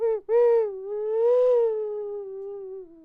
• ocarina 4.wav
Short sample from 3d printed ocarina, recorded with a Sterling ST66.
ocarina_4_QDp.wav